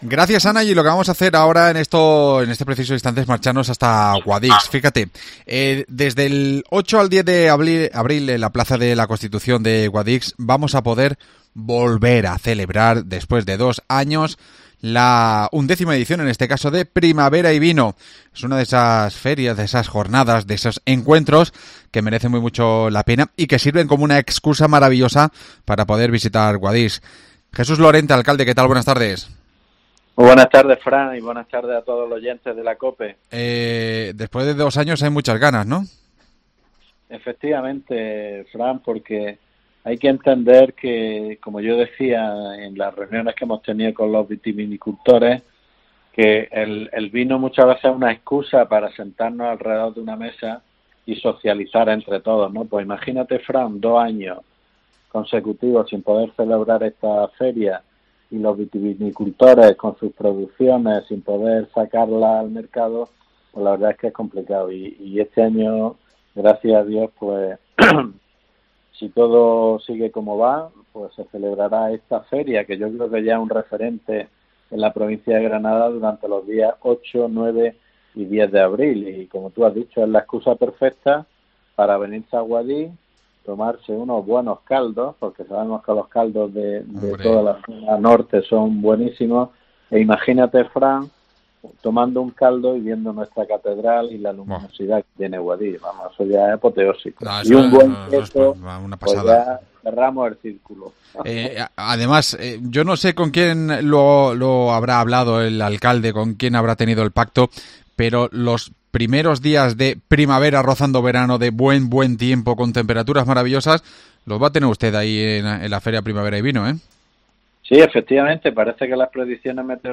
Nos lo cuenta el alcalde accitano Jesús Lorente